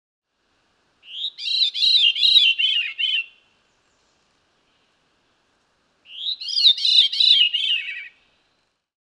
This small thrush of northern forests gets its name from its song: a spiral of descending notes that echoes wistfully through the forest, reminiscent of the sound of Pac-Man meeting a ghost 👻.